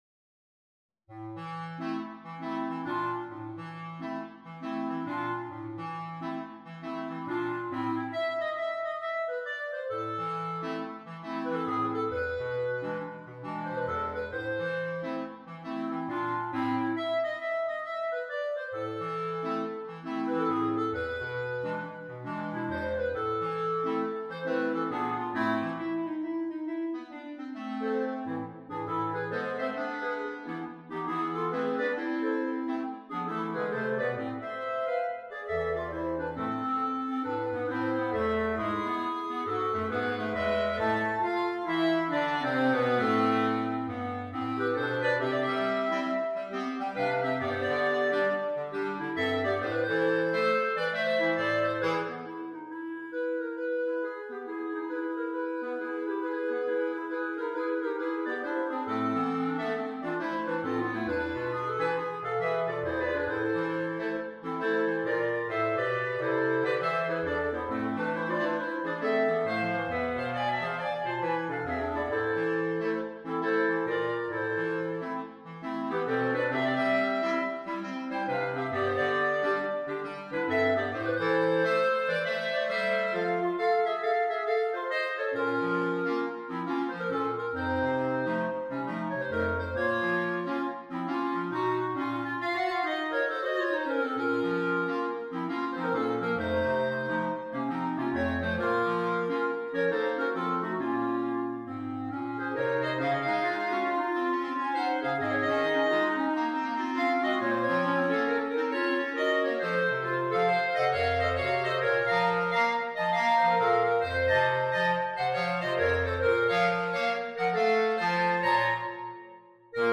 A jazz twist
in 5/4 time, for Clarinet Quartet
Jazz and Blues